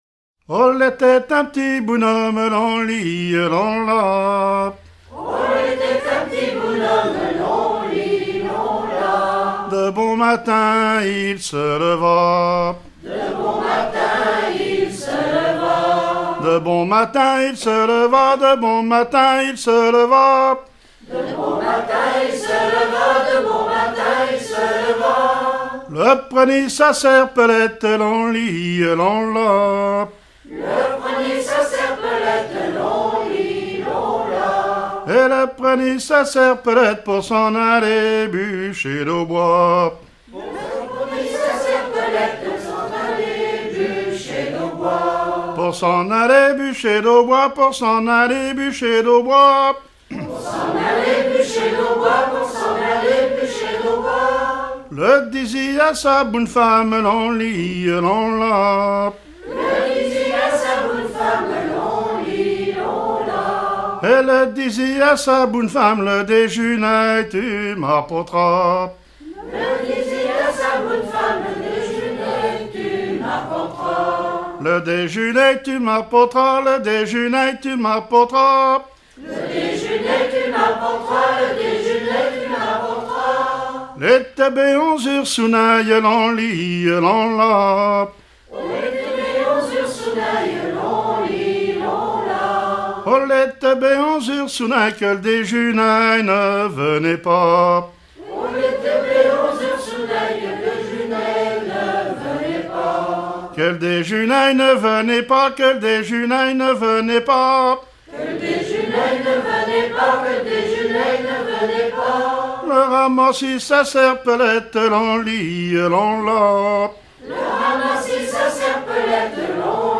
Genre laisse
Catégorie Pièce musicale éditée